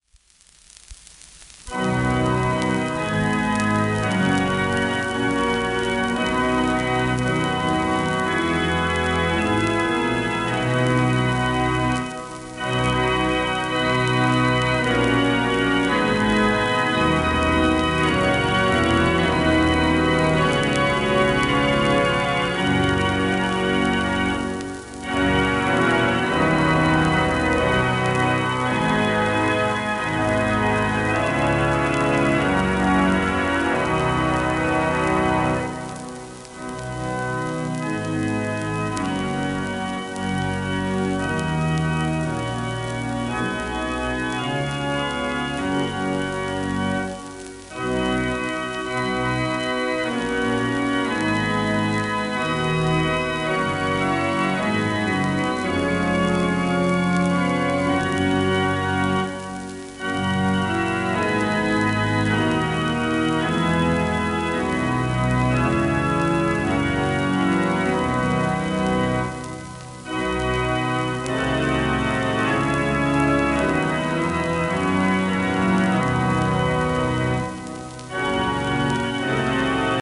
1928年頃の録音。オルガンの多くは教会で録られおり、この盤もそうした物の一つ